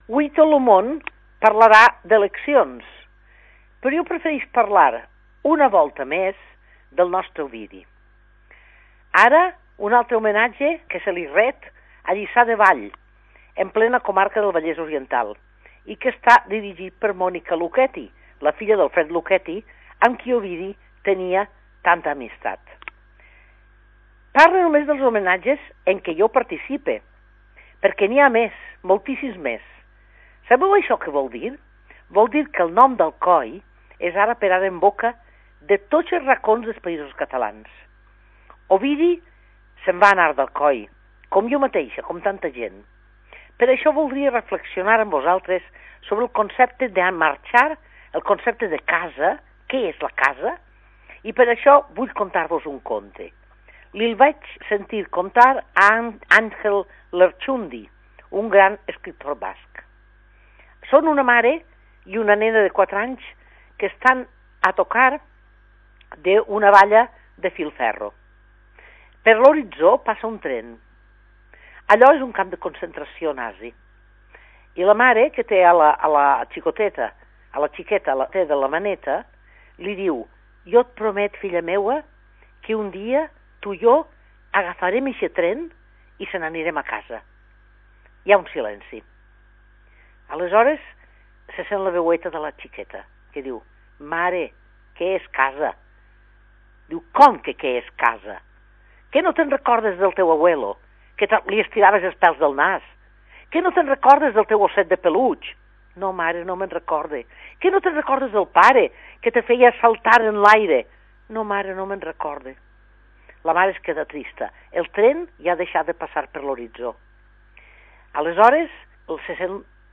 Isabel-Clara Simó és escriptora i filla predilecta d'Alcoi